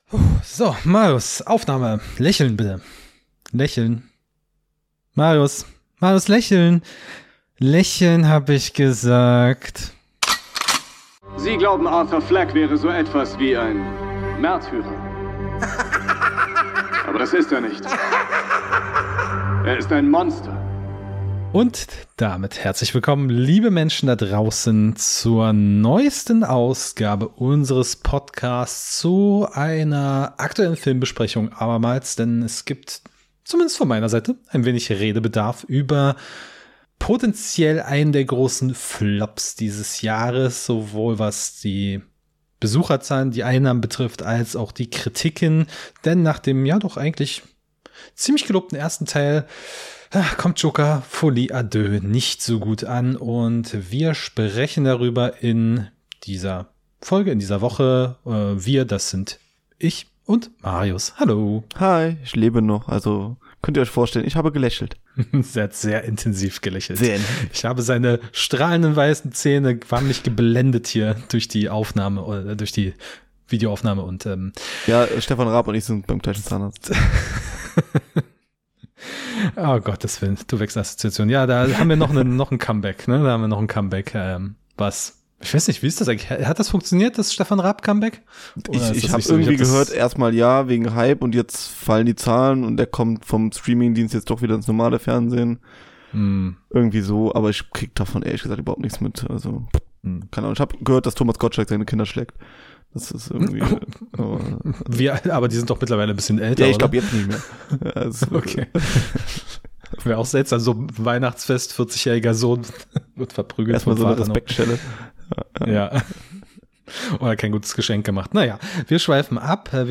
Joker_2_Folie_a_Deux_Review_Talk.mp3